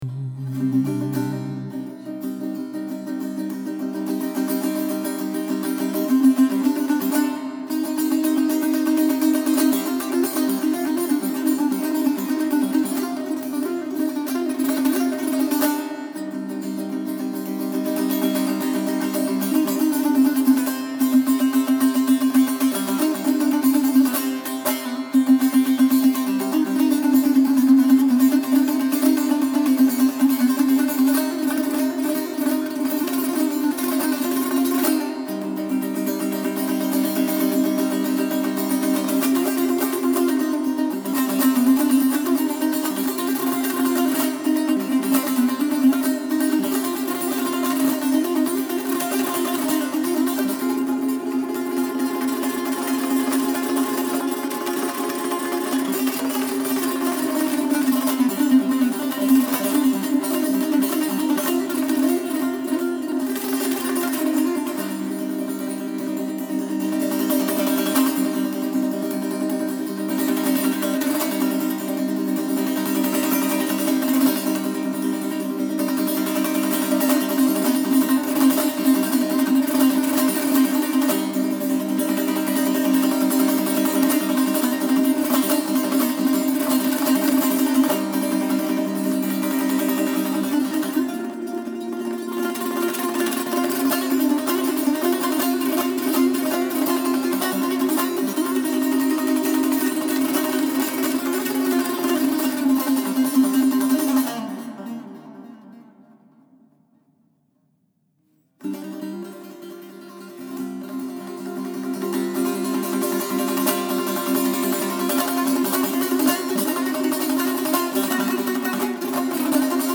Taknavazi Tanbour